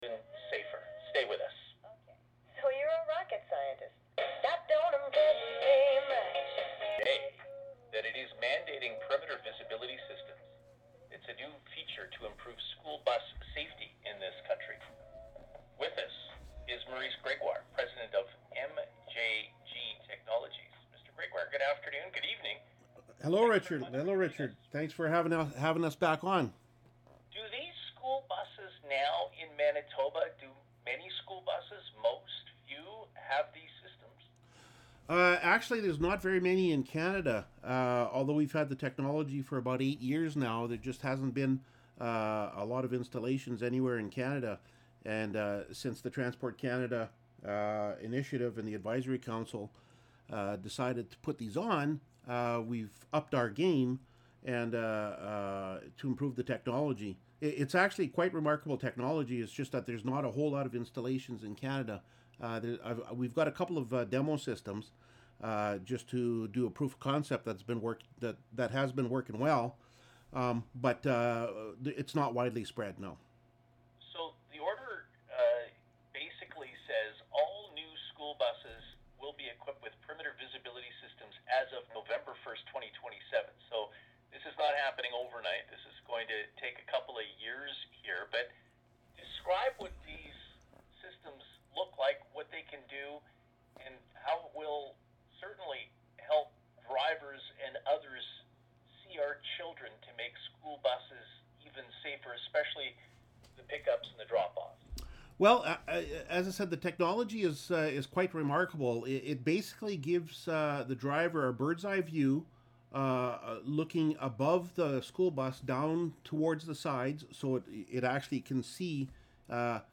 CrossSafe 360 CJOB News Radio Interview Feb 4 2025